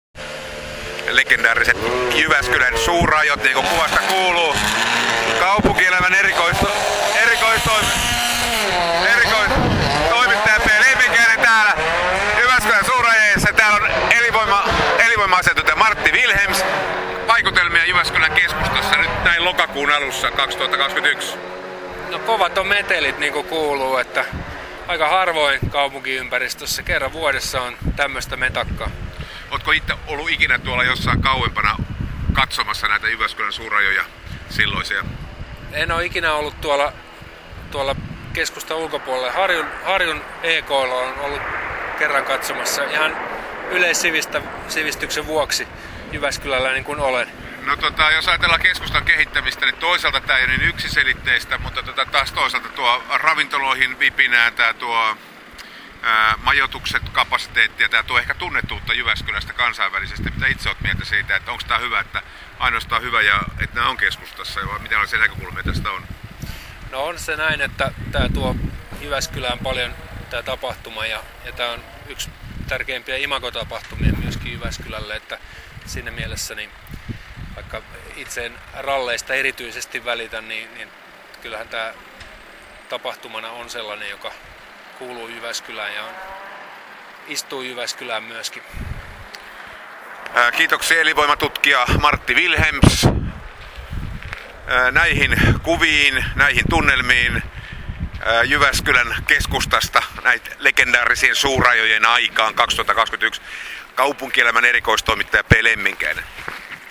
Nyt taivaalla pörrää helikopteri, muodostelma suihkuhävittäjiä leikkaa maisemaa ristiin rastiin ja ralliautot kiihdyttävät ensimmäiselle erikoiskokeelleen. Pauhu hirvittää.